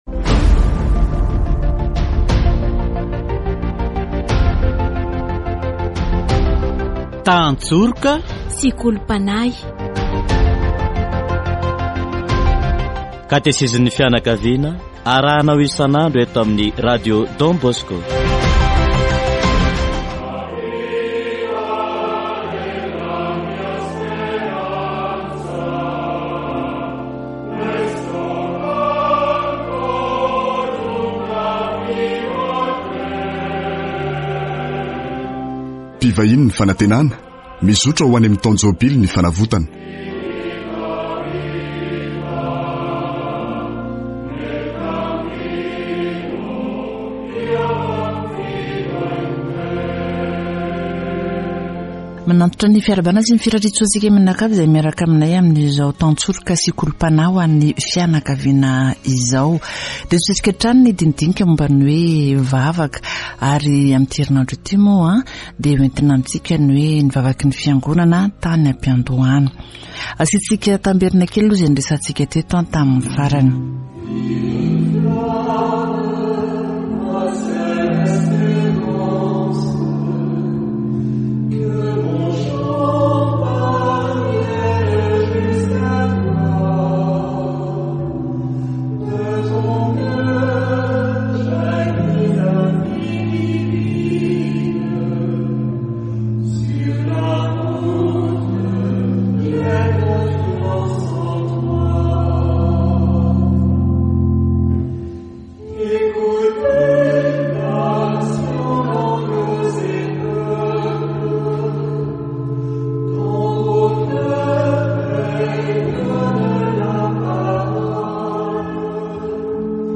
Catégorie : Approfondissement de la foi
Catéchèse sur La prière de l'église, au début